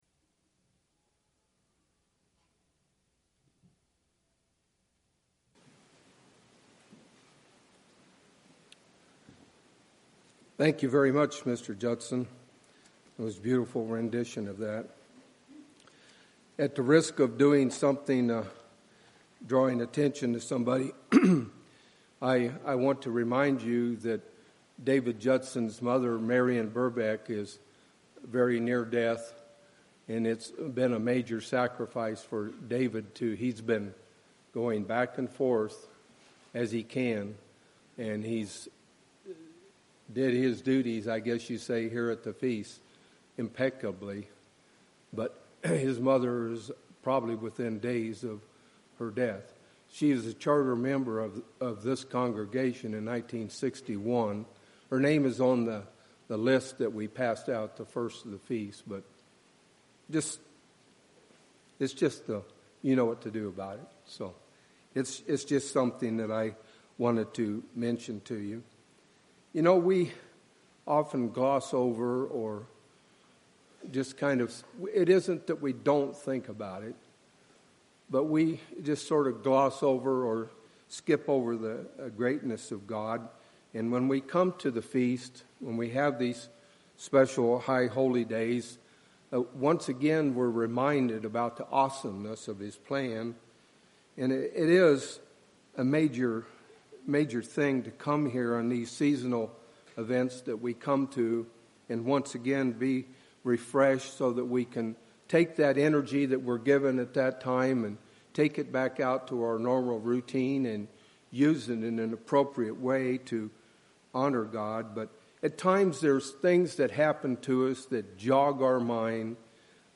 This sermon was given at the Phoenix, Arizona 2017 Feast site.